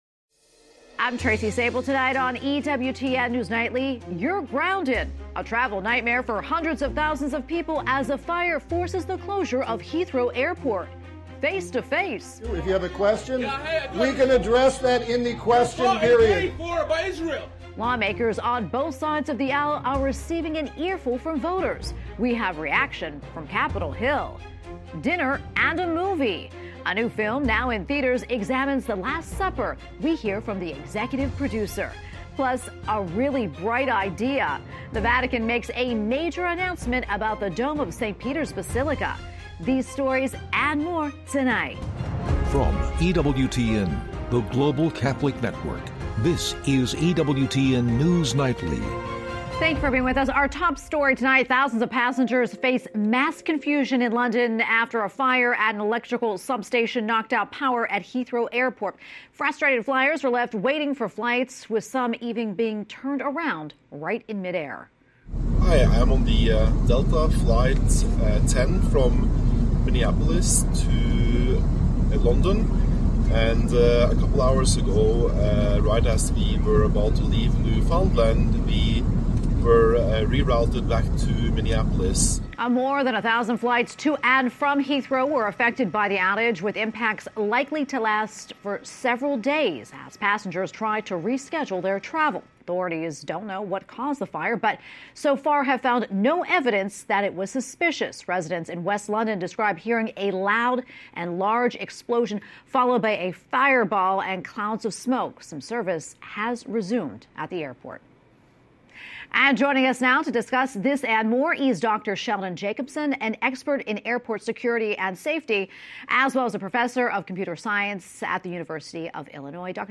EWTN News Nightly is our daily news and analysis program presenting breaking Catholic News worldwide, top stories, and daily reports from the White House, Capitol Hill, and Rome.